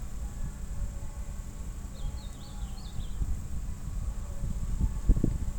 Ultramarine Grosbeak (Cyanoloxia brissonii)
Condition: Wild
Certainty: Recorded vocal